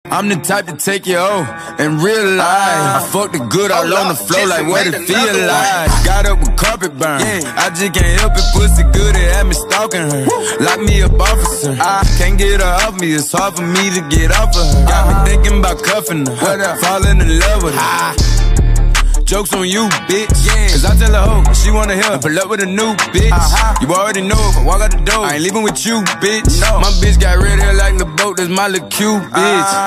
Rap - Hip Hop